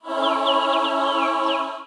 抽奖转盘.mp3